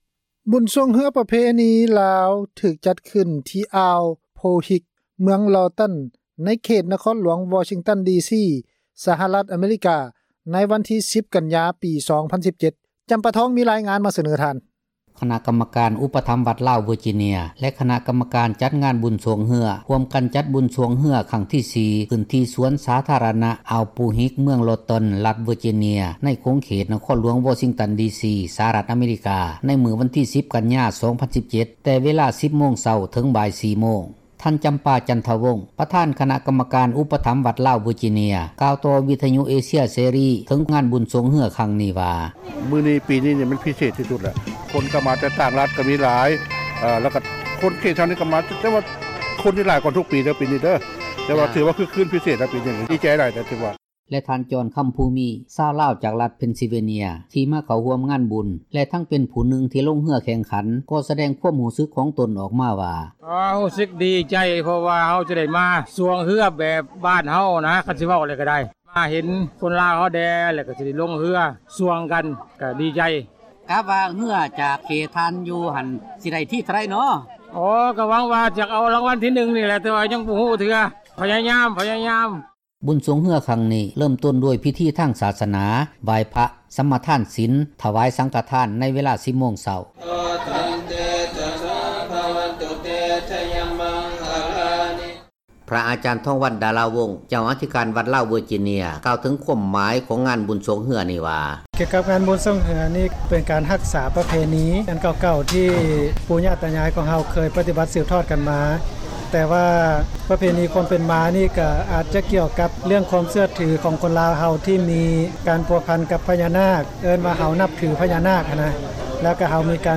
f-boat ບຸນຊ່ວງເຮືອ ປະເພນີລາວ ຖືກຈັດຂຶ້ນທີ່ ອ່າວໂພຫິກ, ເມືອງລໍຕັນ ໃນຂົງເຂດນະຄອນຫຼວງ ວໍຊິງຕັນ ດີຊີ ສະຫະຣັຖ ອະເມຣິກາ ໃນມື້ວັນທີ 10 ກັນຍາ 2017